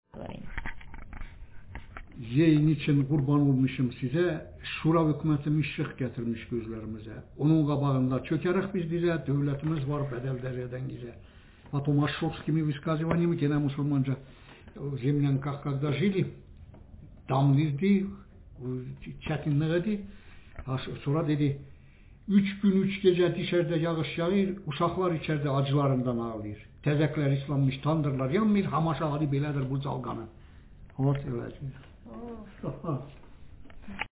Speaker sexm
Text genretraditional narrative